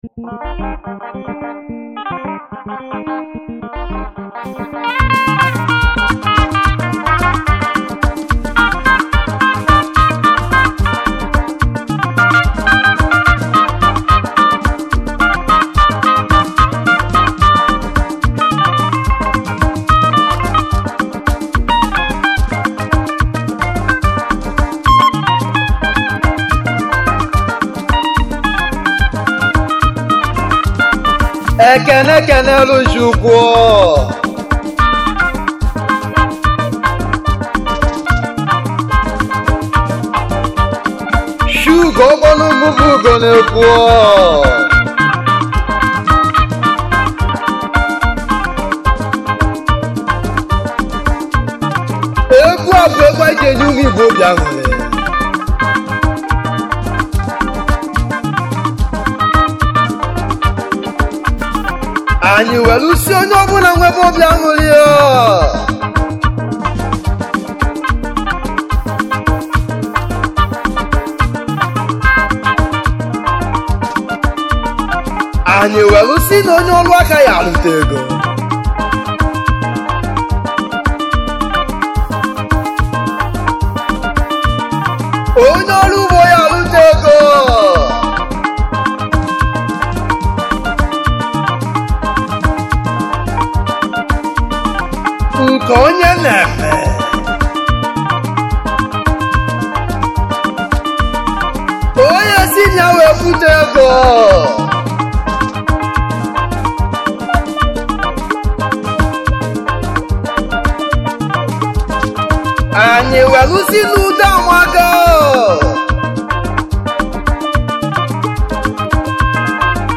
igbo highlife
highlife music band